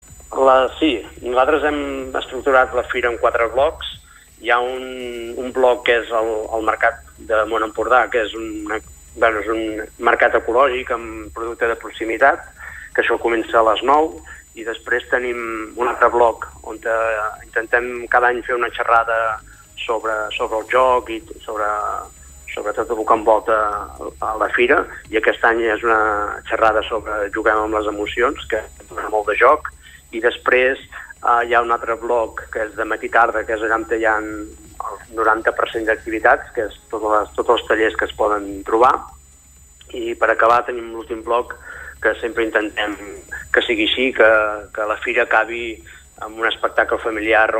Supermatí - entrevistes
Avui ha tornat a Corçà la Fira Ludum, un esdeveniment destinat als jocs per a tots els gustos. Al Supermatí d’ahir ens va visitar el regidor de cultura del municipi, Josep Bofill, per explicar-nos més detalls de la tercera edició d’enguany.